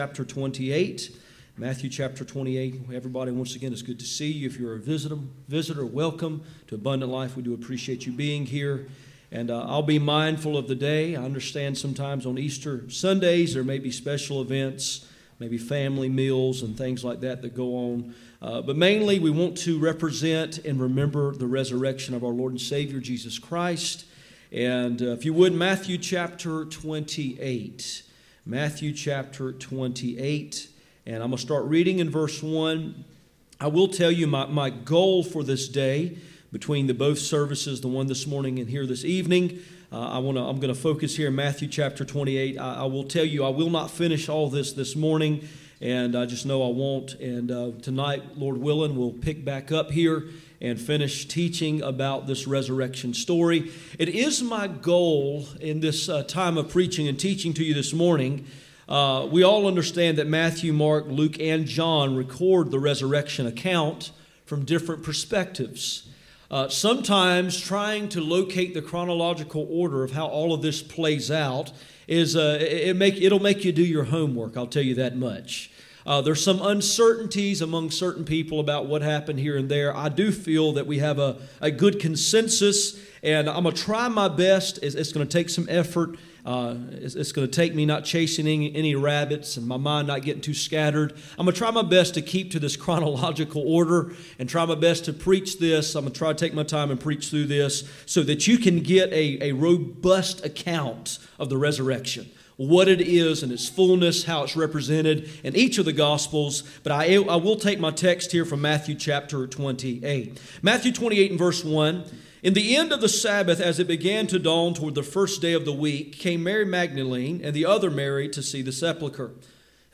Passage: Matthew 28:1-7 Service Type: Sunday Morning